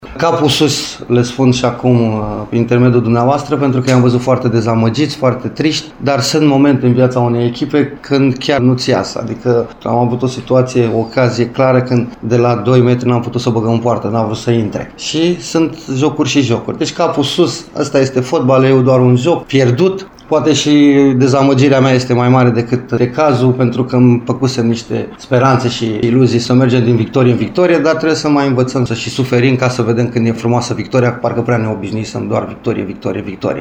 Eșecul cu Ceahlăul i-a afectat pe jucători, dar Flavius Stoican a încercat să îi îmbărbăteze și prin intermediul conferinței de presă: